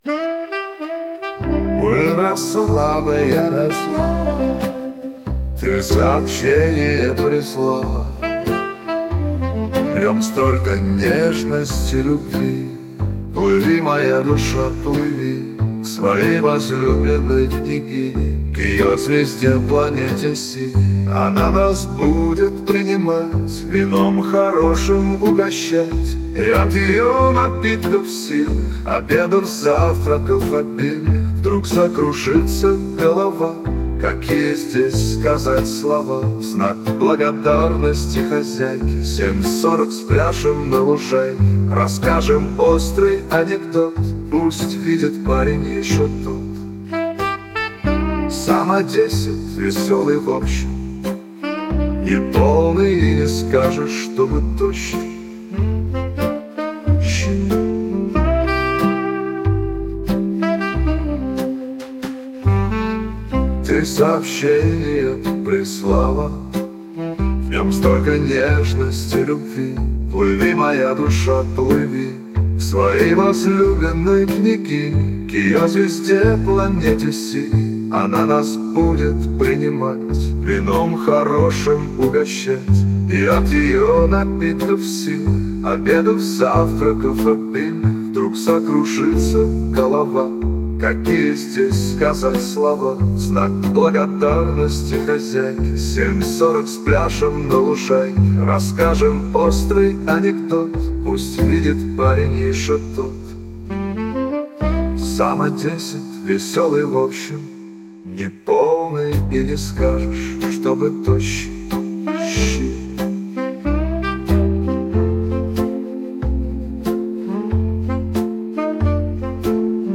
ТИП: Пісня
СТИЛЬОВІ ЖАНРИ: Ліричний
Песня легкая, нежная, отдыхающая! sp